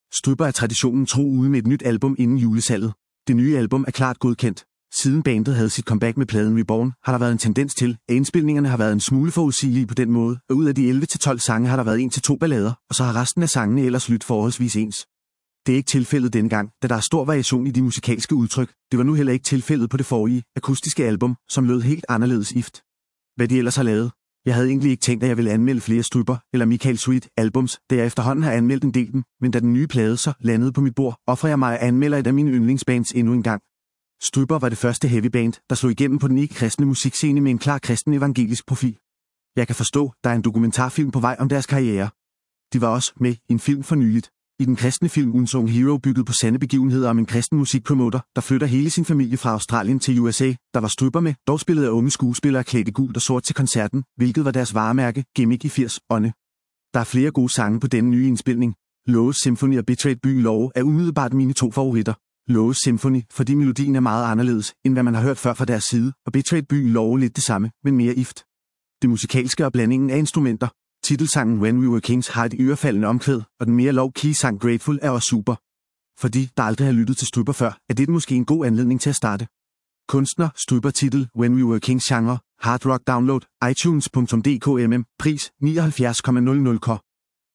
Genre: Hard Rock